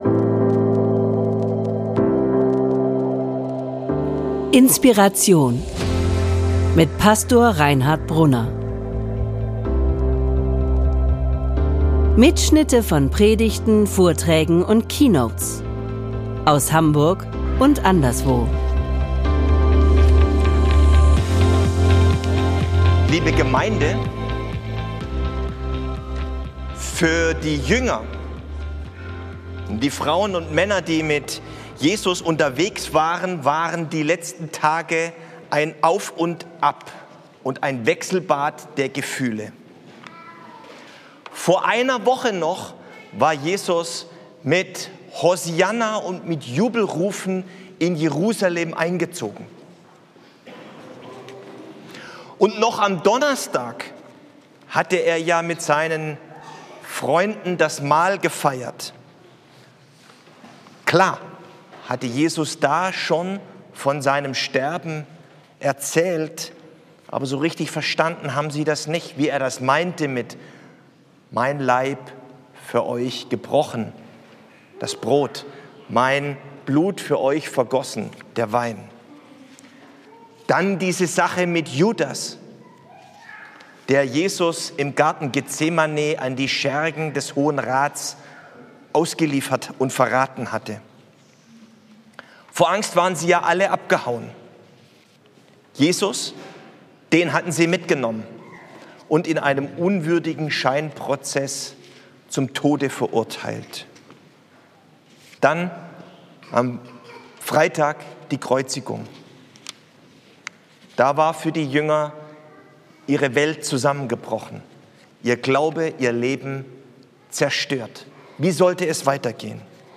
Ostern 2025 - 5 Brand-Sätze um deinen Glauben neu zu entfachen ~ INSPIRATION - Predigten und Keynotes Podcast